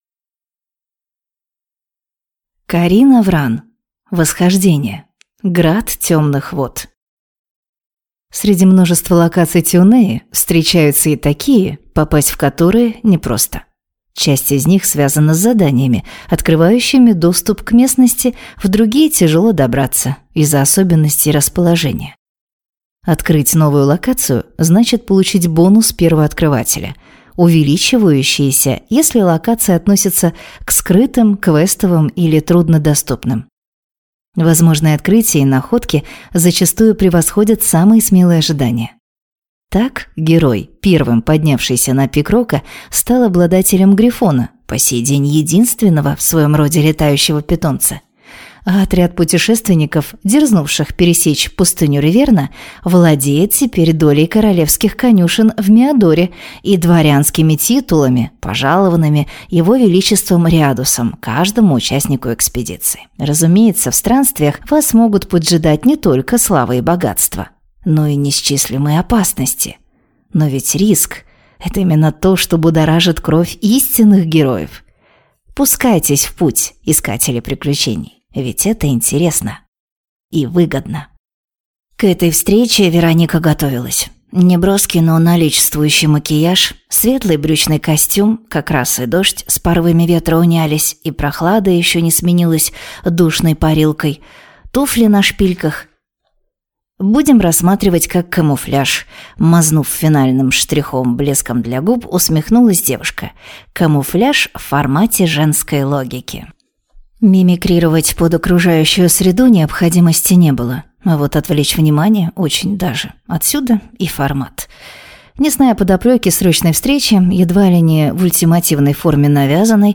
Аудиокнига Град темных вод | Библиотека аудиокниг